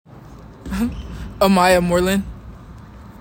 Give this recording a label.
AUDIO PRONUNCIATION: